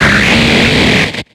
Cri de Libégon dans Pokémon X et Y.